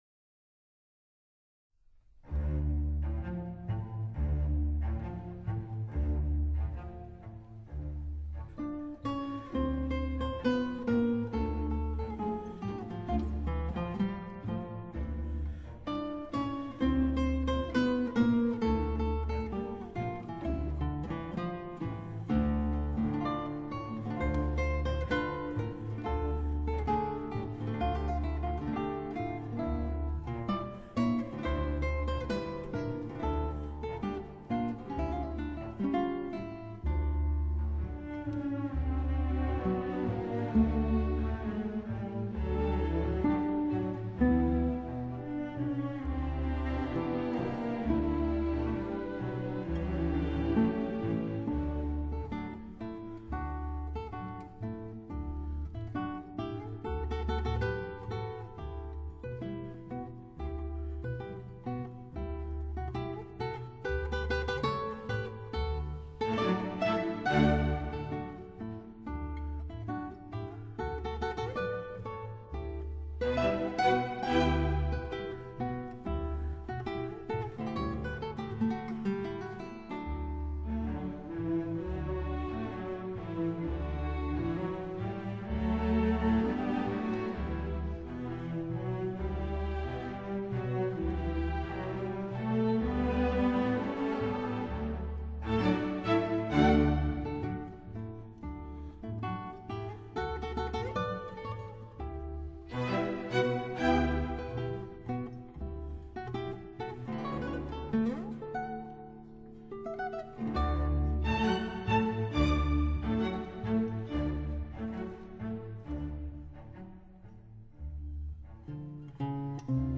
Bizet-Habanera-Carmen-instrumental.mp3